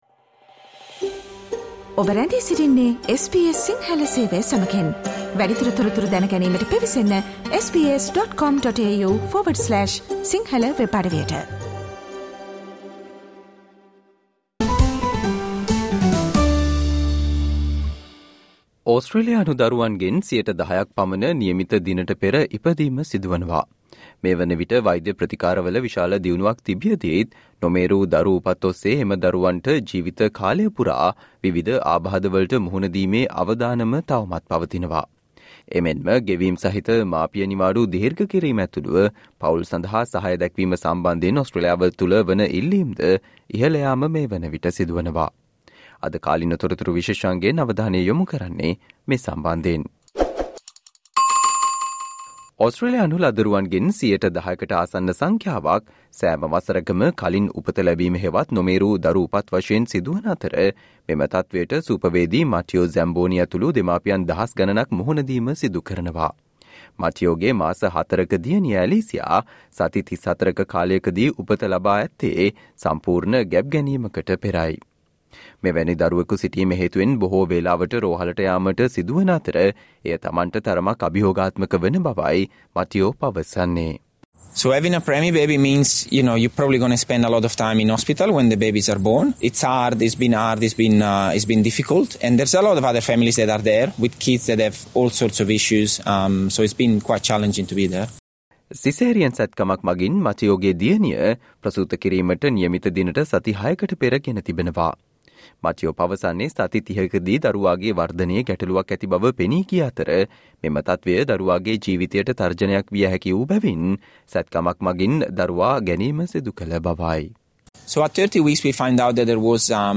Today -27 December, SBS Sinhala Radio current Affair Feature on Renewed push for parents of prems to receive extended paid parental leave